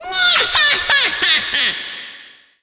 Cosmic Mario's voice clip when entering a level. Also reused for Cosmic Clones in Super Mario Galaxy 2 and Super Mario 3D Land.